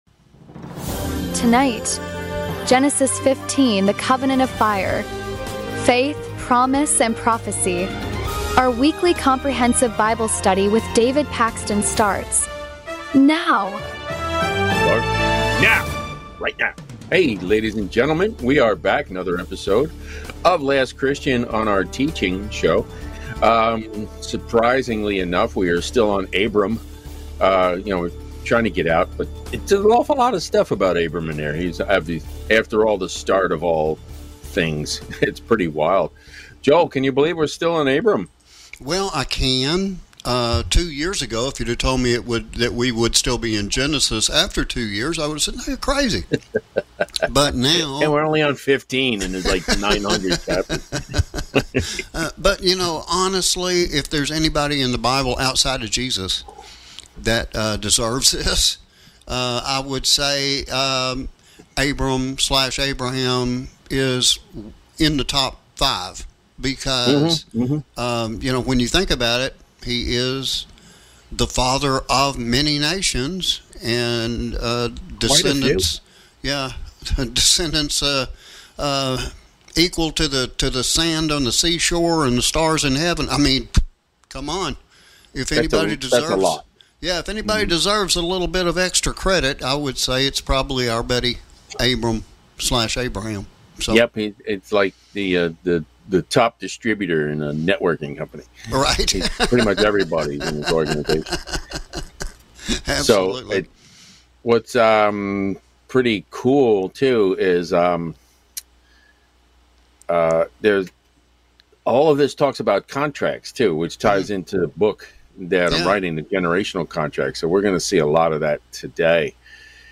Weekly Comprehensive Bible Study